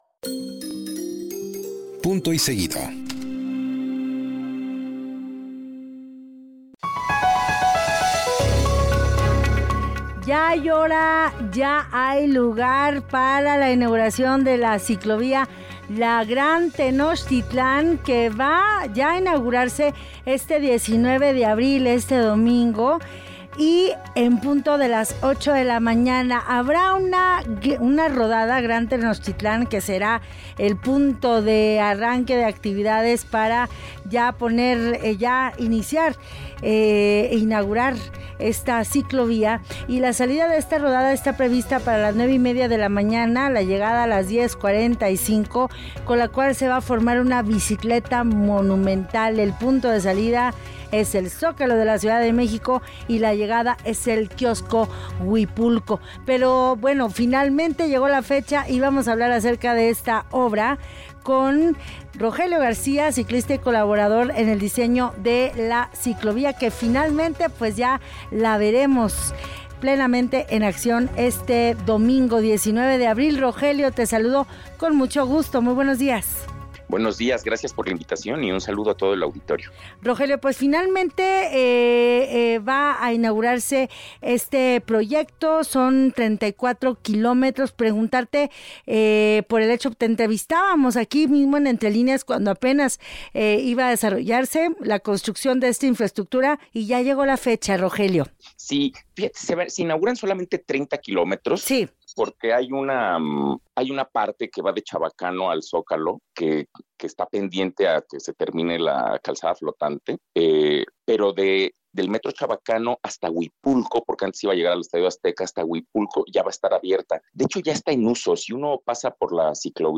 Para entrevista en EntreLíneas